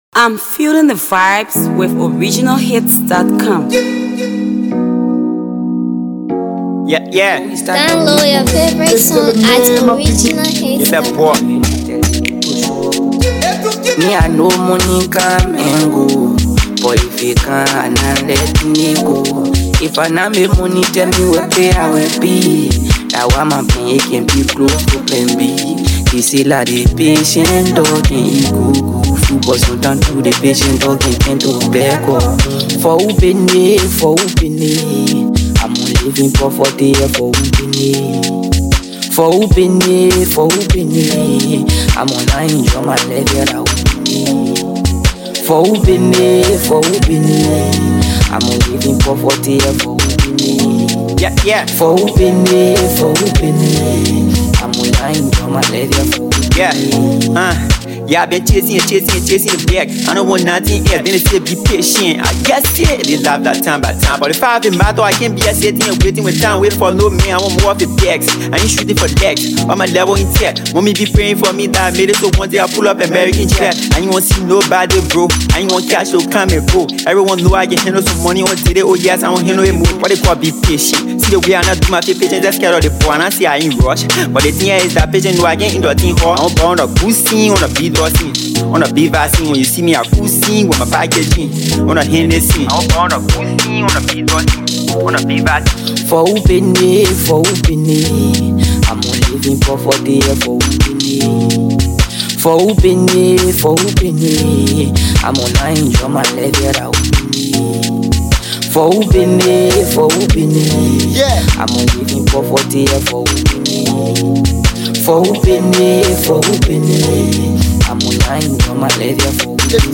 Talented Liberian artist